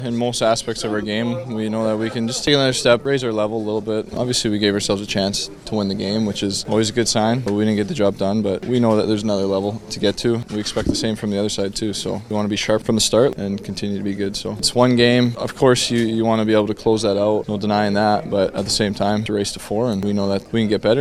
Oilers forward Ryan Nugent-Hopkins, who has 8 points in six playoff games so far spoke to media ahead of their game two matchup, mentioning they need to do a better job of closing games out.